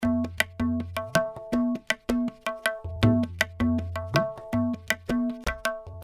Tabla loops 80 bpm
indian tabla loops in 80 bpm, 25 loops in total in this item
This is an Indian tabla drum loops (scale A), playing a variety of styles.
Played by a professional tabla player.
The tabla was recorded using one of the best microphone on the market, The AKG C-12 VR microphone. The loops are mono with no EQ, EFFECT or DYNAMICS, but exported stereo for easy Drop and play .